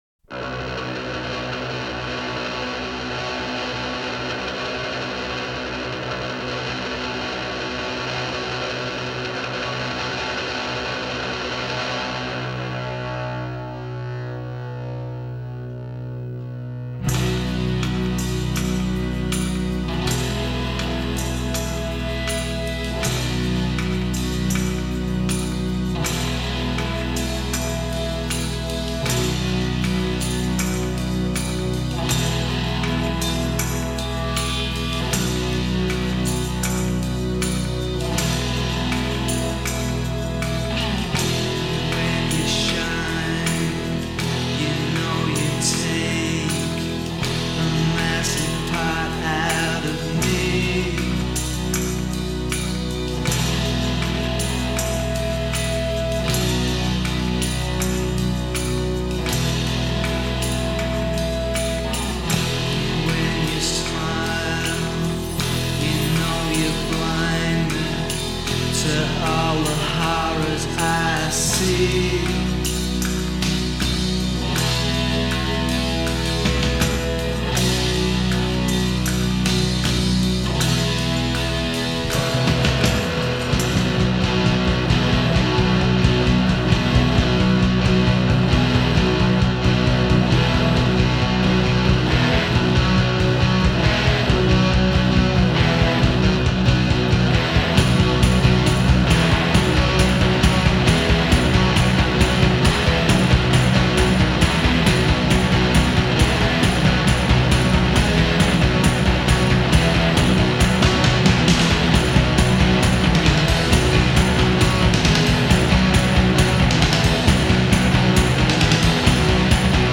Experimental/Garage/Space Rock